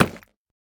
Minecraft Version Minecraft Version 25w18a Latest Release | Latest Snapshot 25w18a / assets / minecraft / sounds / block / nether_bricks / break3.ogg Compare With Compare With Latest Release | Latest Snapshot
break3.ogg